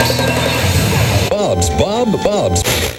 80BPM RAD7-R.wav